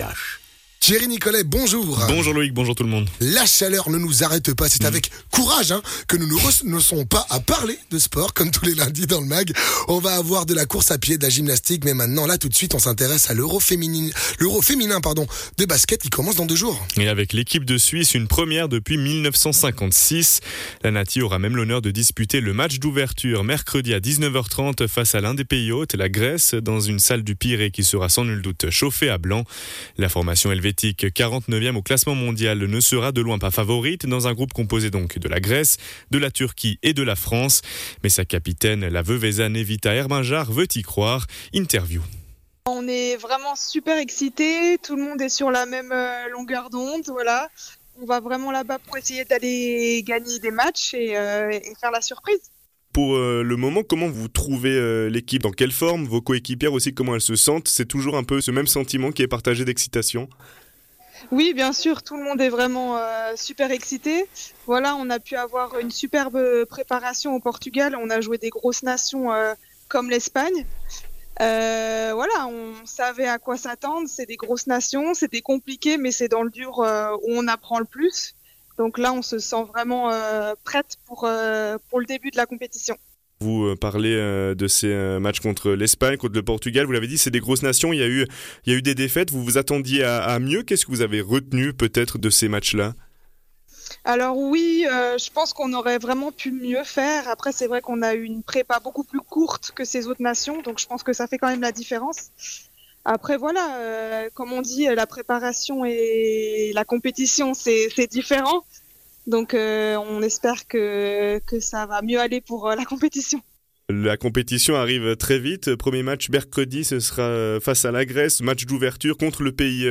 basketteuse